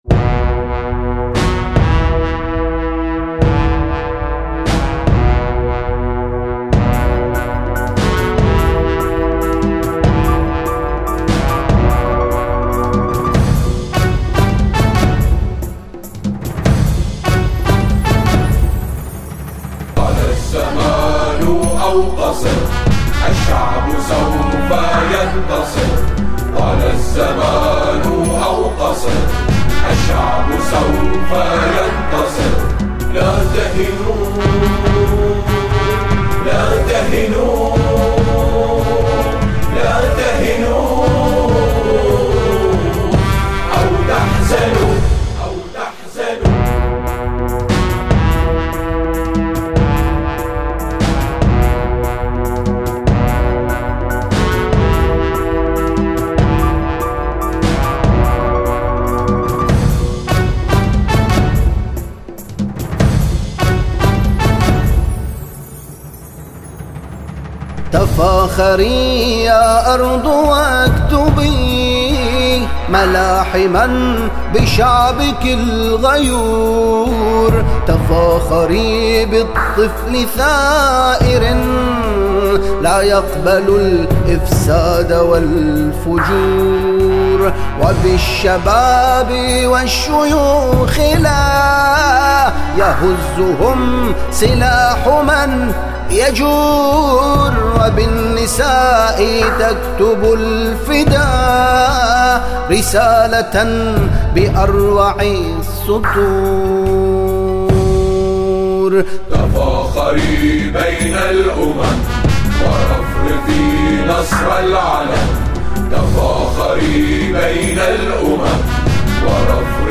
أناشيد بحرينية انشودة وطنية